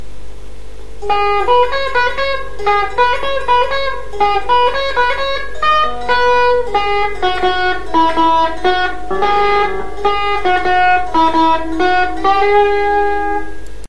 играл я на гитаре, как всегда записи немного налажал)
главная пианинная тема